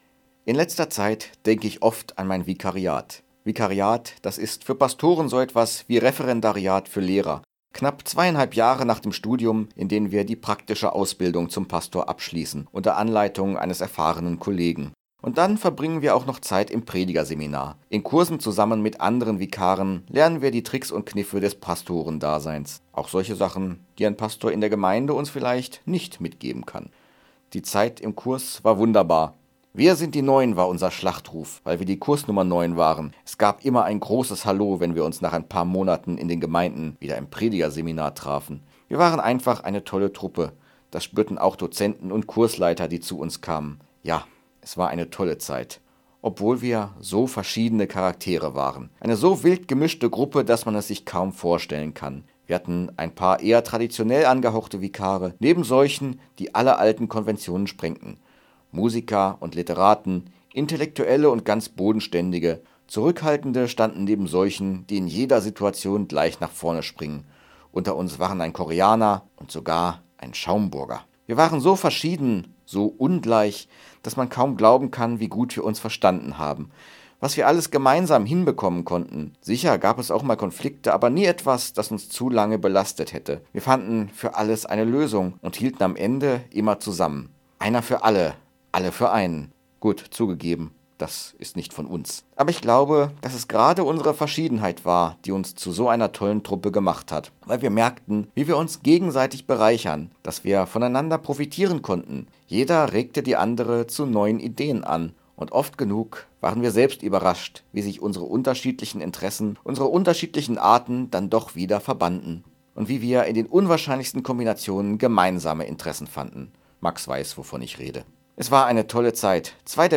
Radioandacht vom 22. Mai